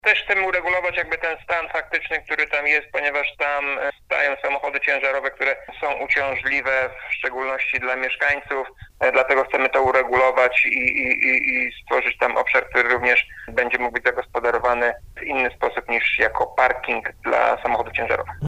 O szczegółach mówi wiceprezydent miasta Dariusz Lesicki: